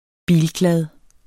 Udtale [ ˈbiːlˌglað ]